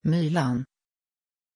Pronunciation of Mylann
pronunciation-mylann-sv.mp3